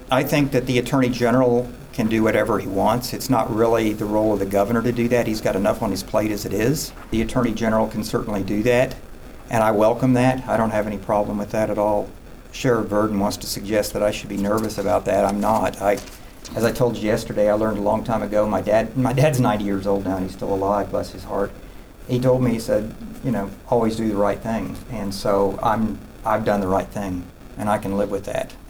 Fisher gave a statement and fielded questions himself.